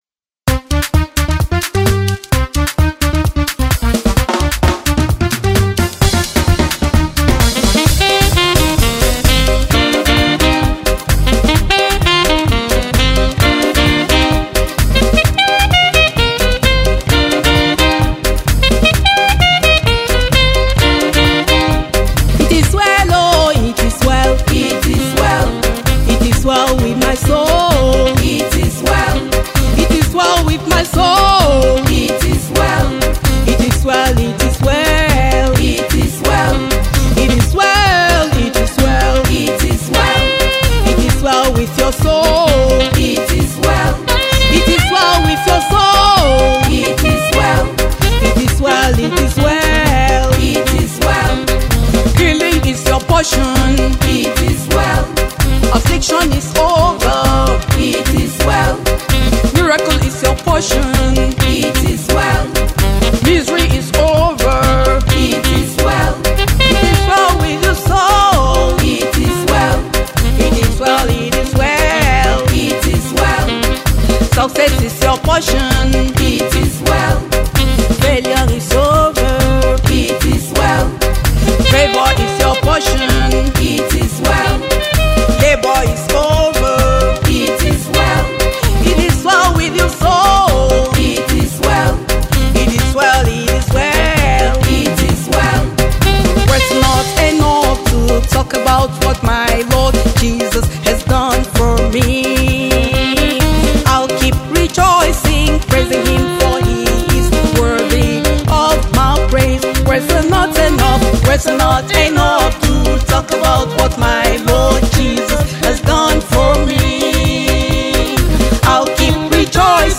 Gospel Music
It is soul-lifting and faith-provoking